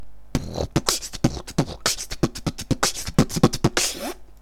бит состоит из звуков:
Brr b kch tt b t b kch tt b t b t b kch tt b tt b t b kch вууть